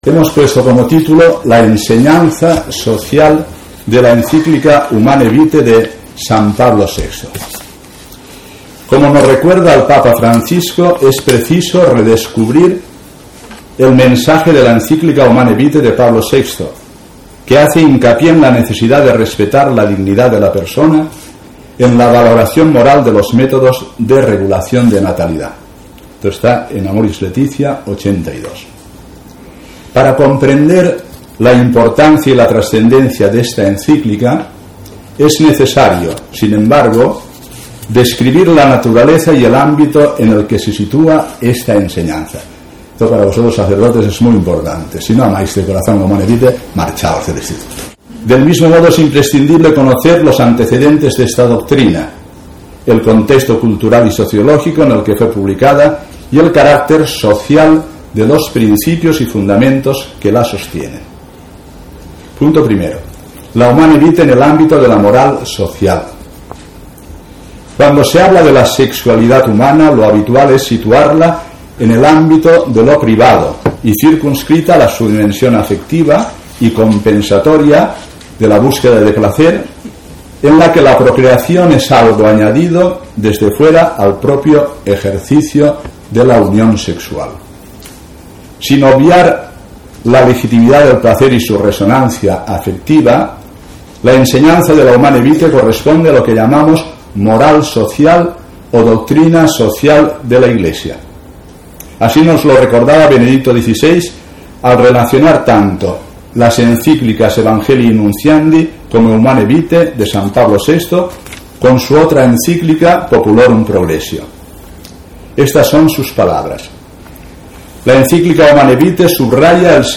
El lunes 13 de noviembre, se celebró la Jornada Lateranense en la Sección Extra-Urbana de Valencia del Pontificio Instituto Teológico Juan Pablo II para Ciencias del Matrimonio y la Familia.